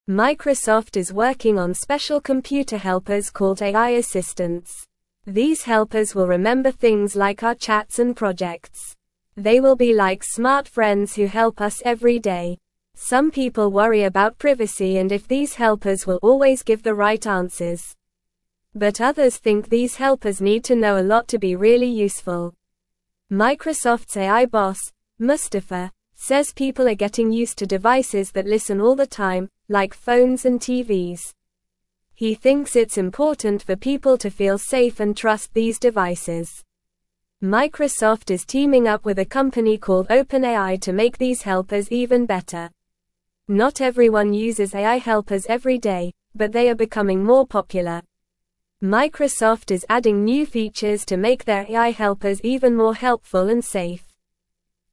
Normal
English-Newsroom-Beginner-NORMAL-Reading-Microsoft-is-Making-Smart-Friends-to-Help-You.mp3